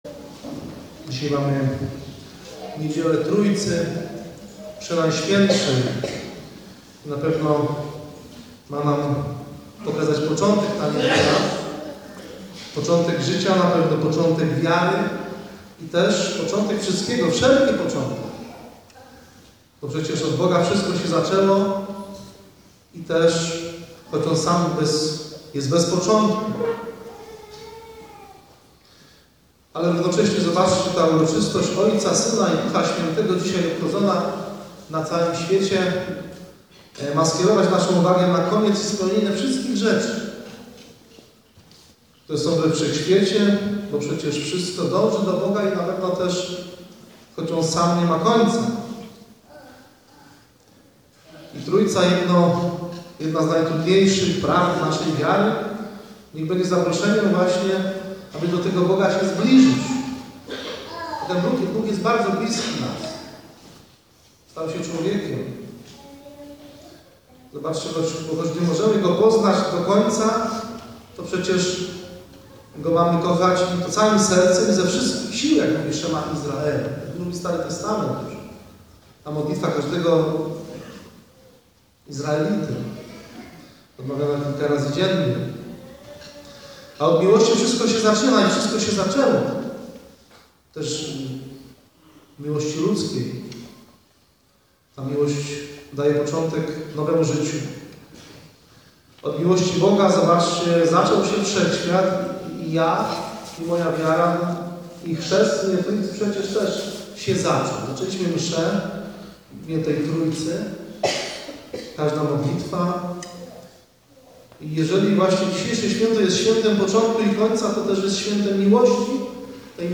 Msza św., suma – homilia